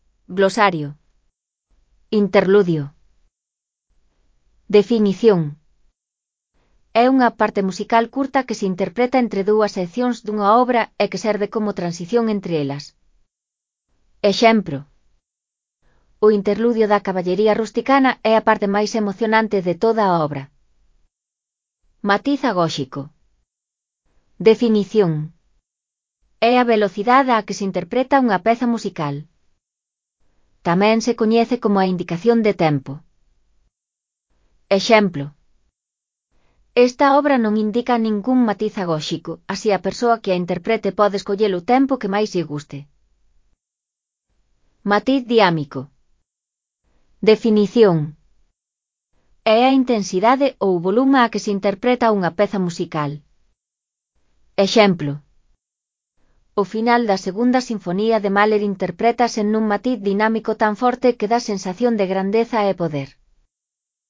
2. Batucada
Nesta fase vas coñecer a Batucada, un estilo cheo de enerxía e de ritmo con instrumentos de percusión. A coordinación e o traballo en equipo son esenciais para conseguir un resultado tan vibrante e cheo de vida.